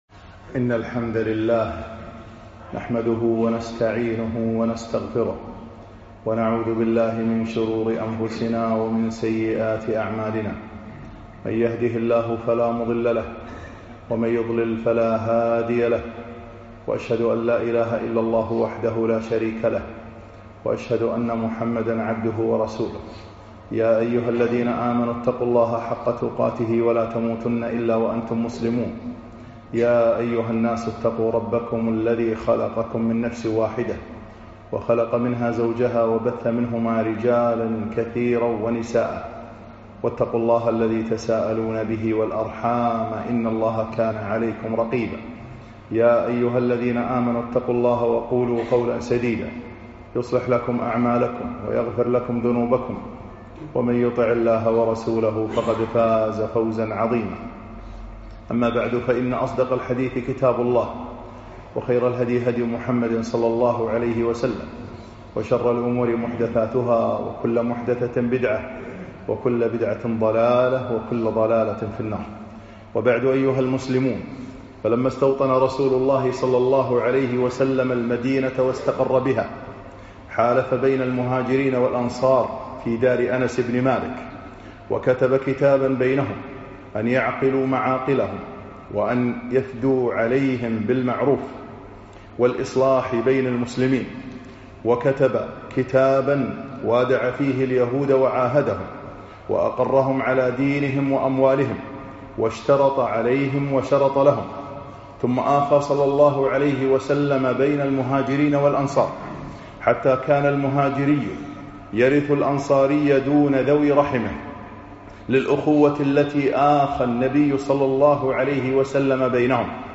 خطب السيرة النبوية 12